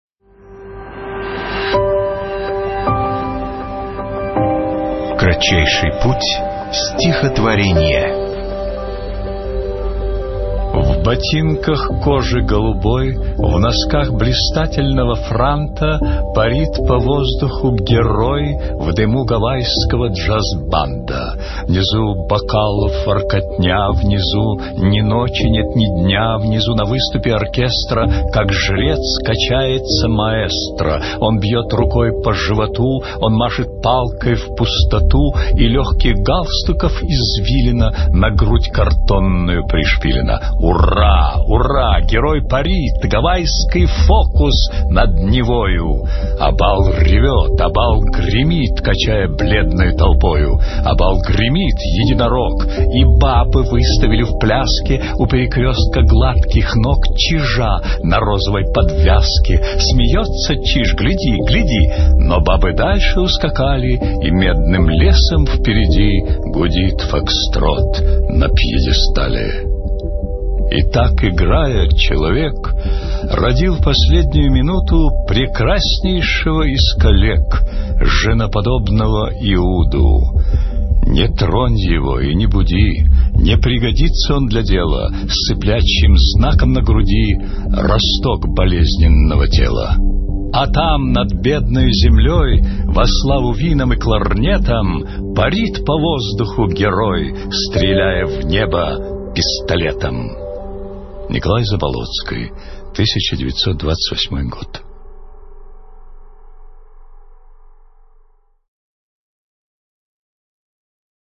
Zabolockiy-Fokstrot-chitaet-Nikolay-Marton-stih-club-ru.mp3